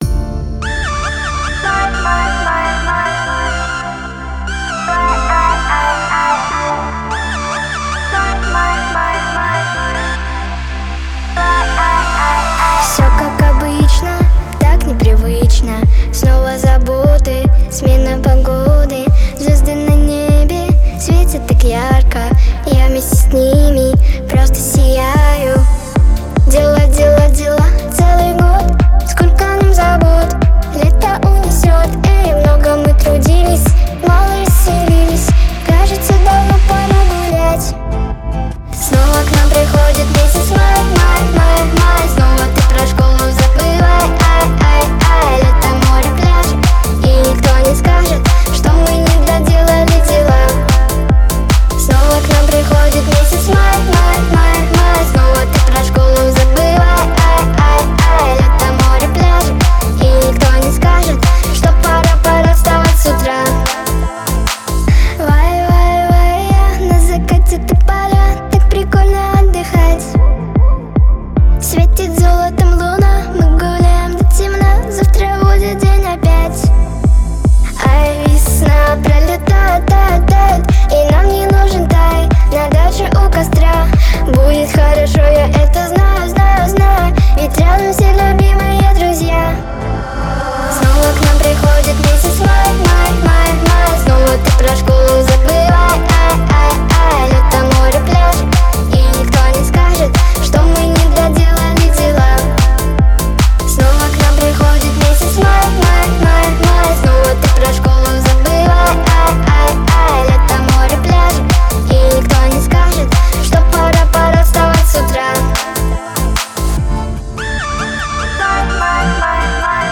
• Качество: Хорошее
• Категория: Детские песни
детская дискотека